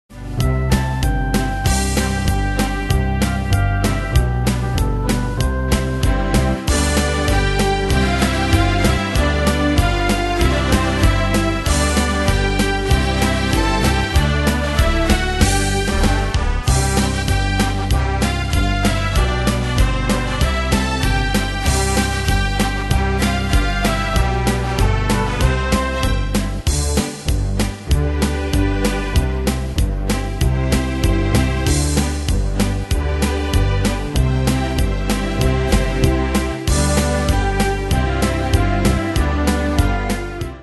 Style: Retro Ane/Year: 1965 Tempo: 96 Durée/Time: 3.29
Danse/Dance: Pop Cat Id.
Pro Backing Tracks